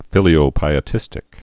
(fĭlē-ō-pīĭ-tĭstĭk)